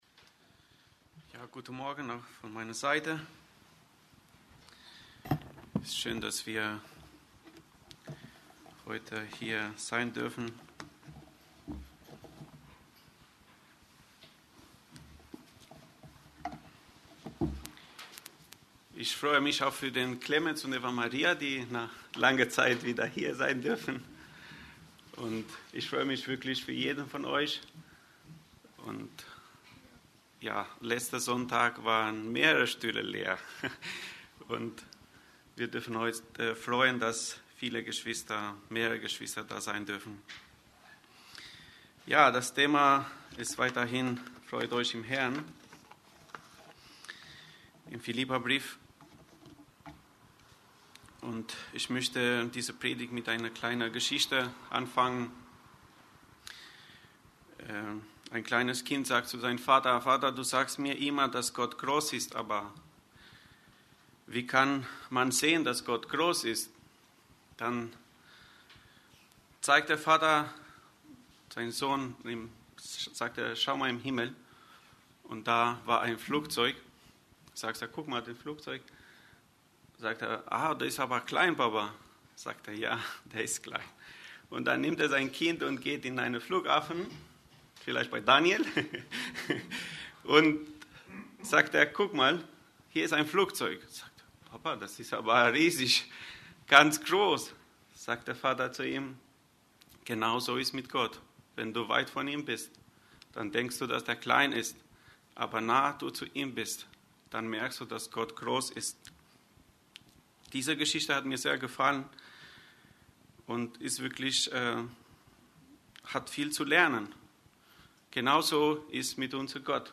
Aus der Predigtreihe: "Freut euch"